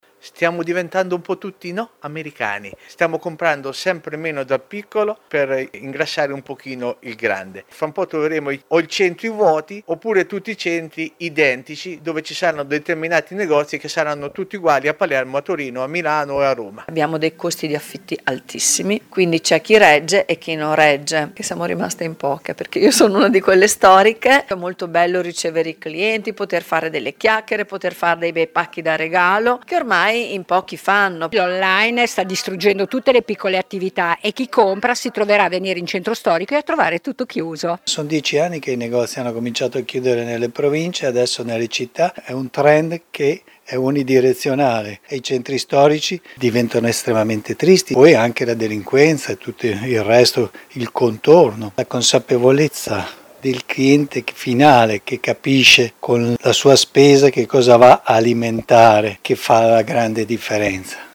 VOX-CHIUSURA-NEGOZI.mp3